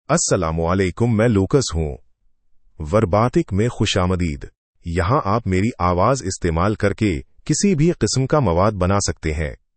MaleUrdu (India)
Lucas is a male AI voice for Urdu (India).
Voice sample
Listen to Lucas's male Urdu voice.
Lucas delivers clear pronunciation with authentic India Urdu intonation, making your content sound professionally produced.